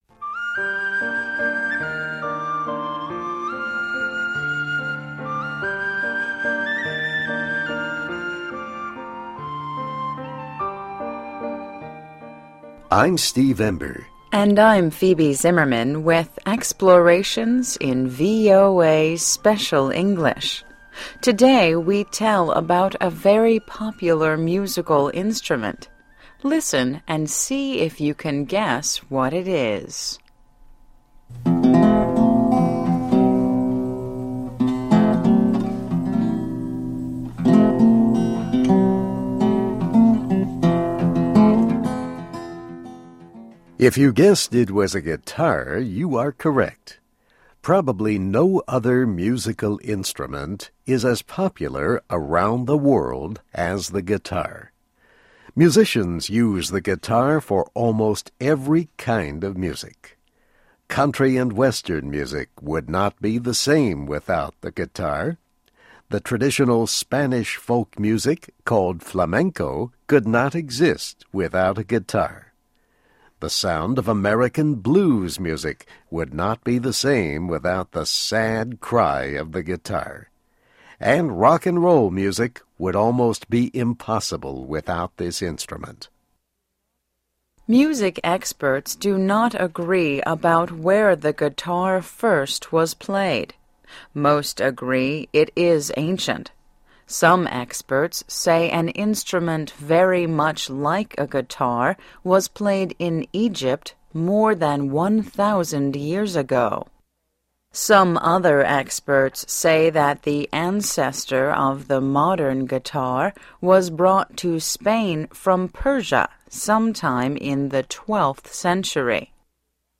Today we tell about a very popular musical instrument. Listen and see if you can guess what it is.